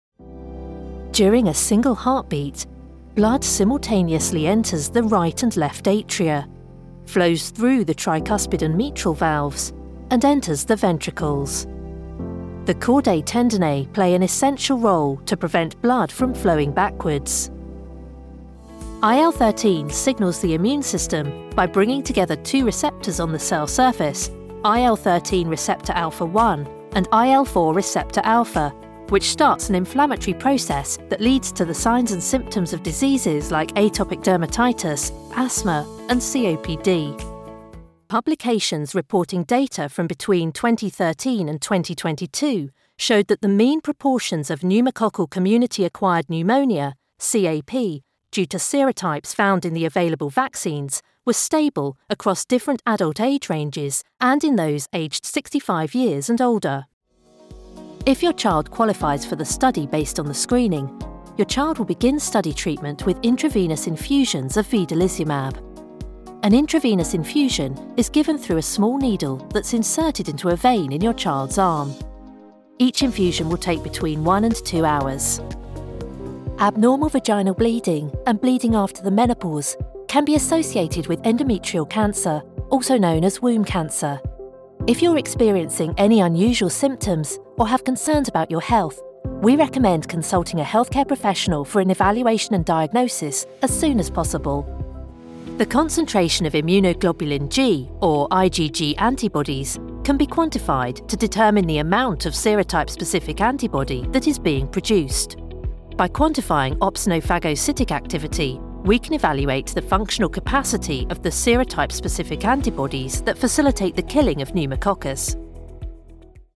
Narración médica
Mi acento es británico neutro, con tonos tanto de RP como de Londres que pueden ser naturalmente formales o informales según el estilo requerido.
Tengo un estudio de grabación casero especialmente diseñado, lo que significa que puedo producir audio de calidad de transmisión rápidamente y a un precio competitivo.
Micrófono: Rode NT1